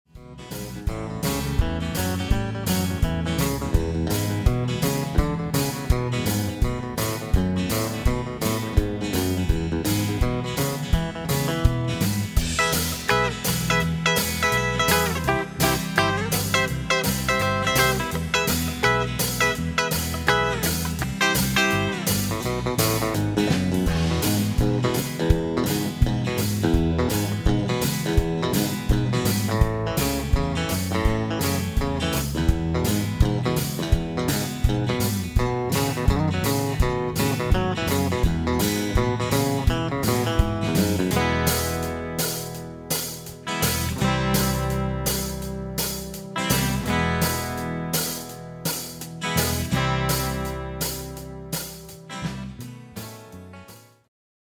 lesson sample